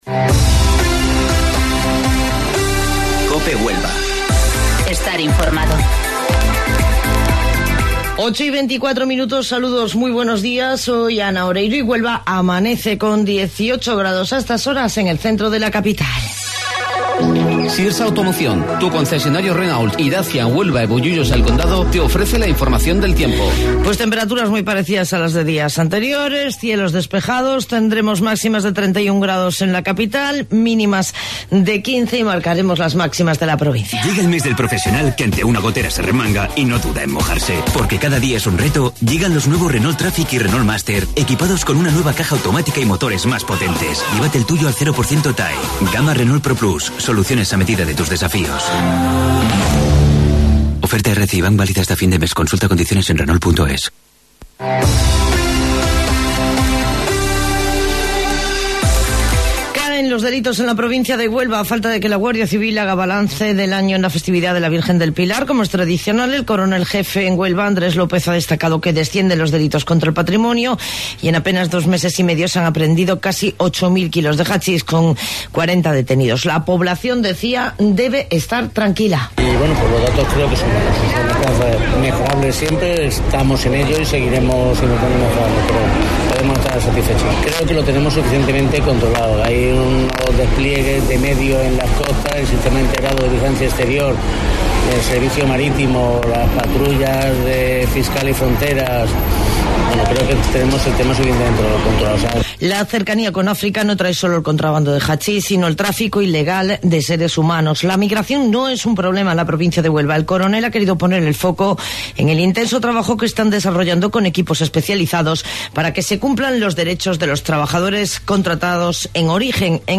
AUDIO: Informativo Local 08:25 del 9 de Octubre